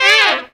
HARM RIFF 10.wav